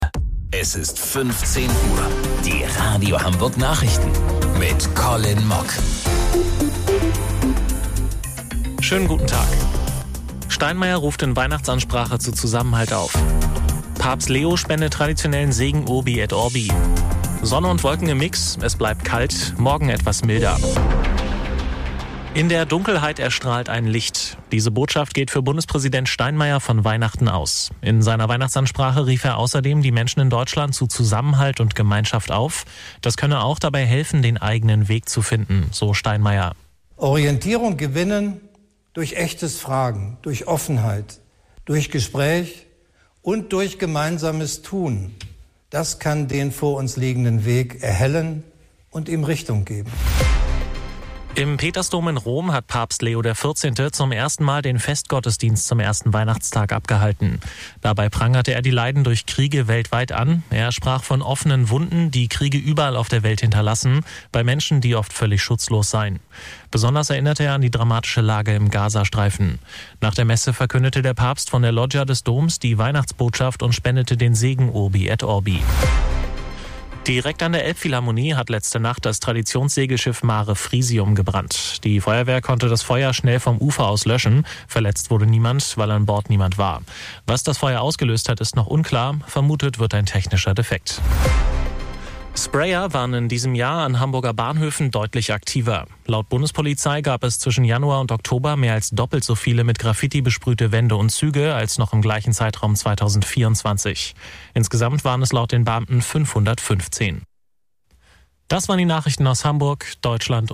Radio Hamburg Nachrichten vom 25.12.2025 um 15 Uhr